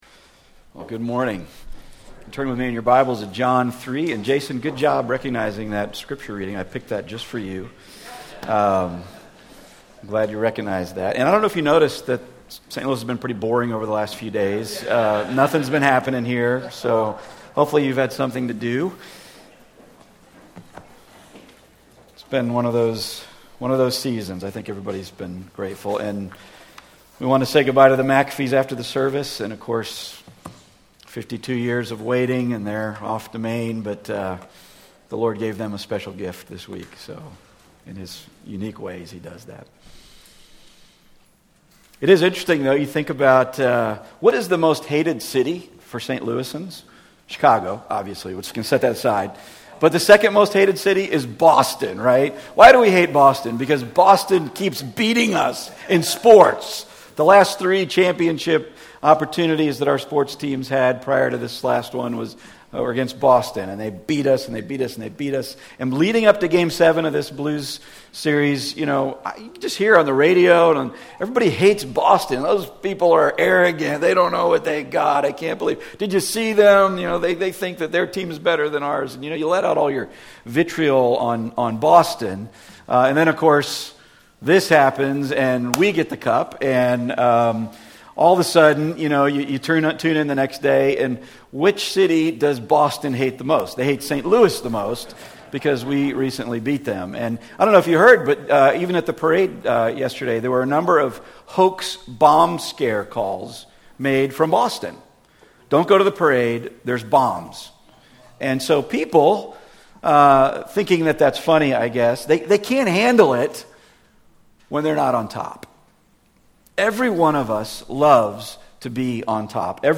The Book of John Passage: John 3:22-36 Service Type: Weekly Sunday